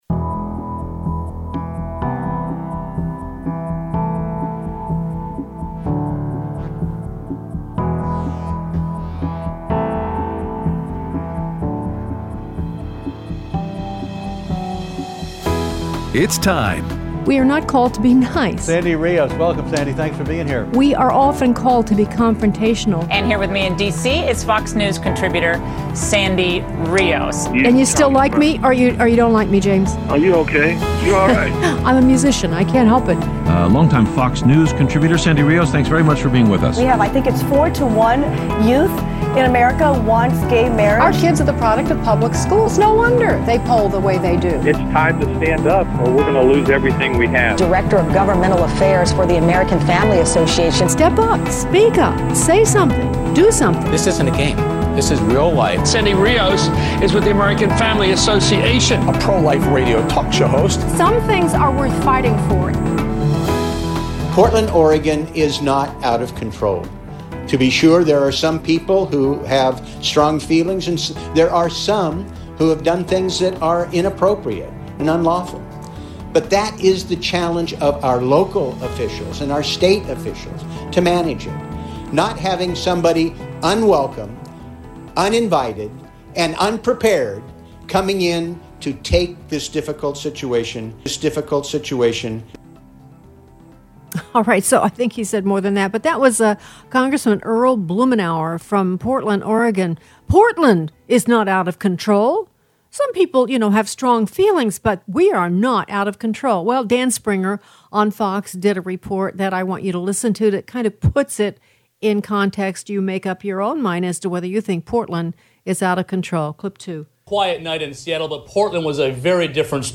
Aired Thursday 7/23/20 on AFR 7:05AM - 8:00AM CST